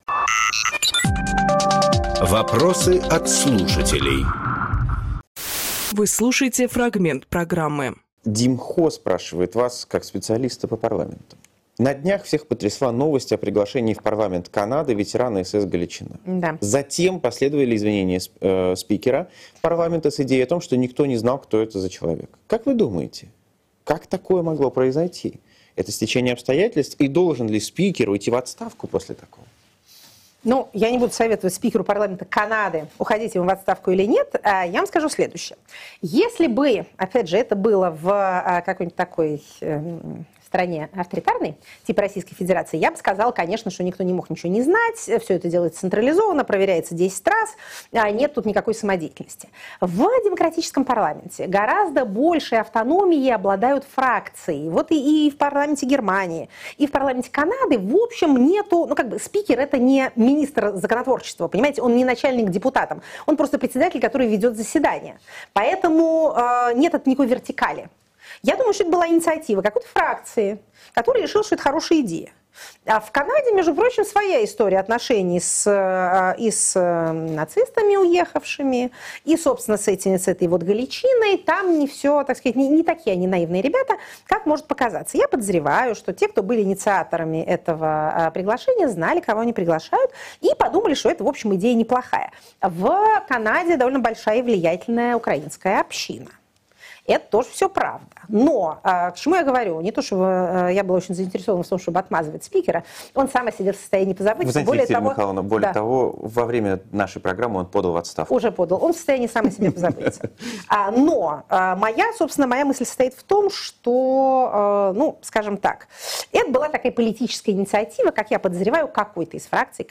Екатерина Шульманполитолог
Фрагмент эфира от 26.09.23